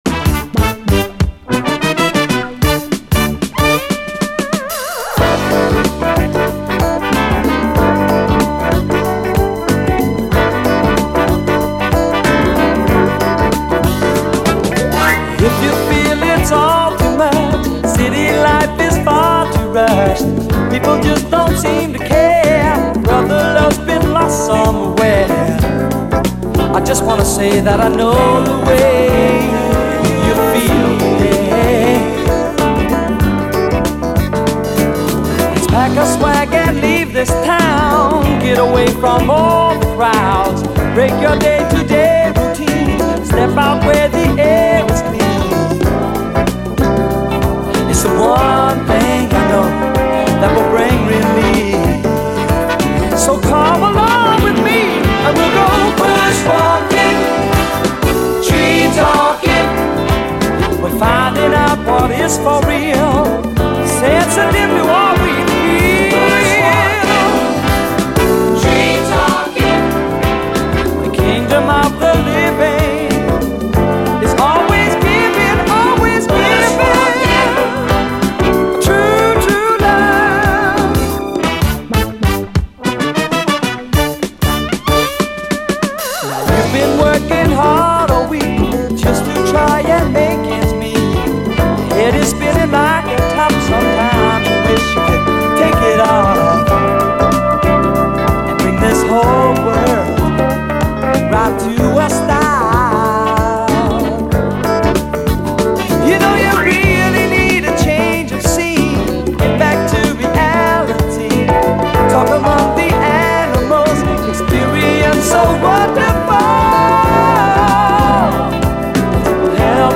SOUL, 70's～ SOUL, 7INCH
オーストラリア産の爽快ミディアム・ソウル人気曲！